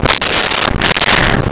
Barf
barf.wav